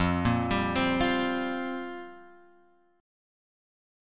Fsus2sus4 chord {1 1 x 0 1 1} chord